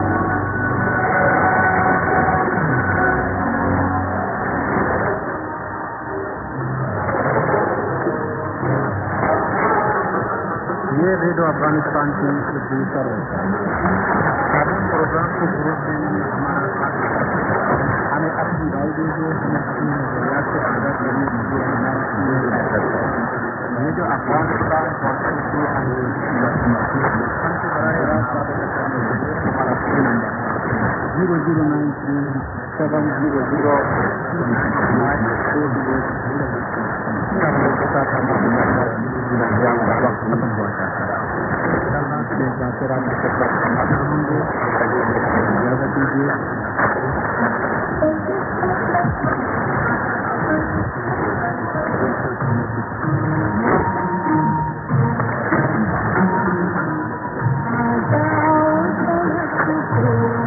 Urdu End> ->ST+ID+SKJ(man)->